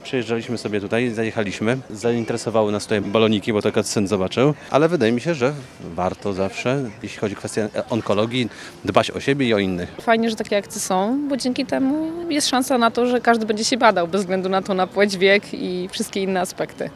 Dlaczego warto pamiętać o zdrowiu? O to gości festynu pytał nasz reporter.